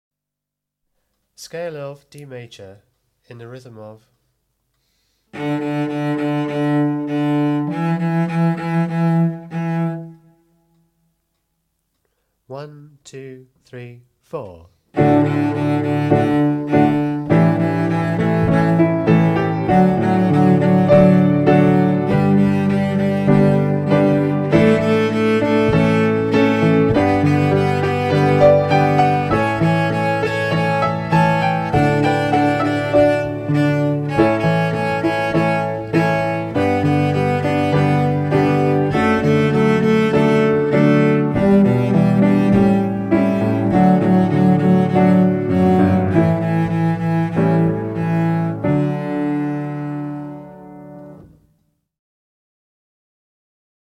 48 D-major scale (Cello)